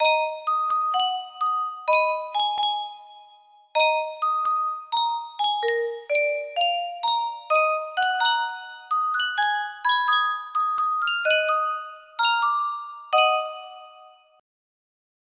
Full range 60